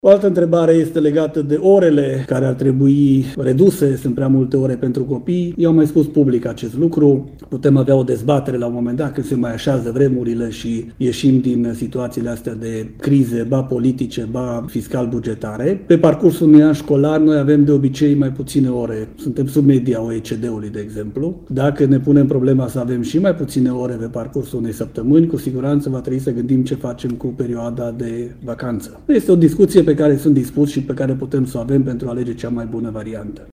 La prezentarea raportului săptămânal, ministrul Educației le-a răspuns și celor care se plâng că elevii au prea multe ore la școală.